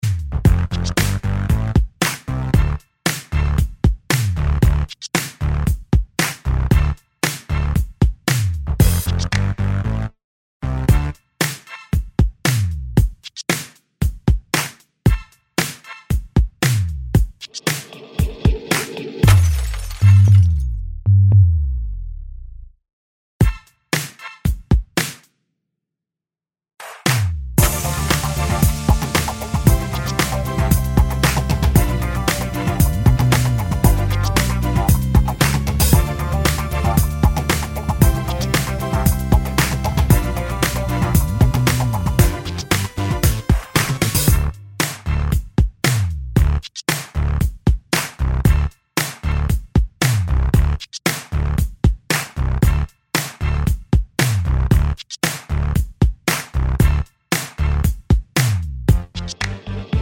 no Backing Vocals no Vocoder Pop (2010s) 3:03 Buy £1.50